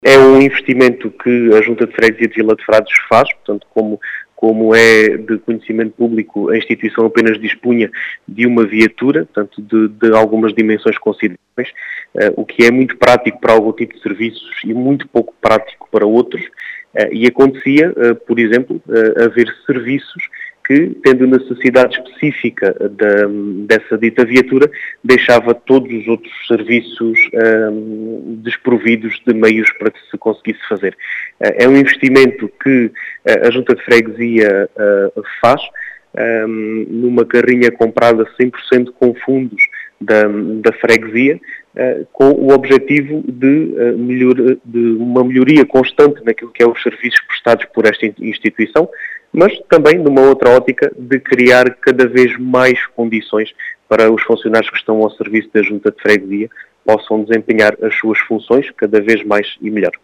As explicações foram deixadas na Rádio Vidigueira, por Diogo Conqueiro, o presidente da junta de freguesia de Vila de Frades.